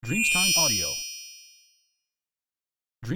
Carillon magico di scintillio di aumento di periodo del ghiaccio
• SFX